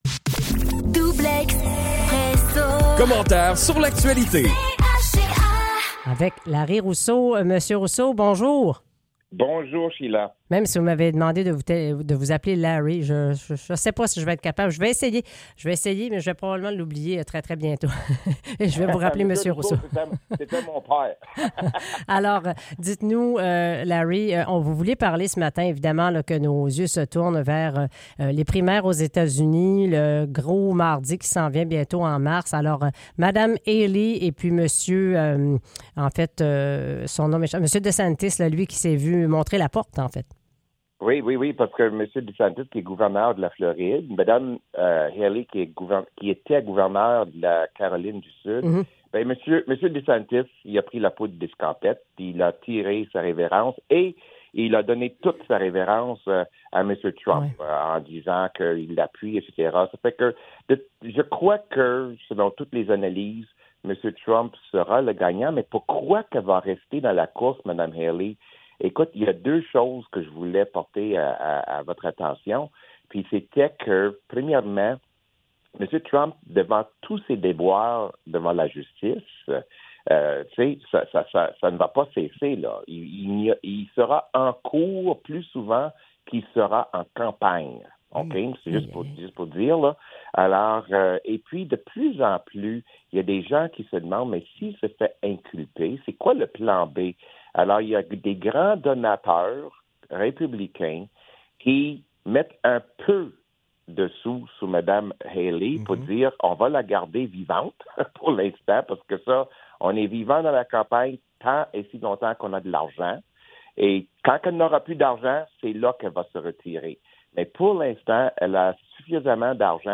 Le commentaire d'actualité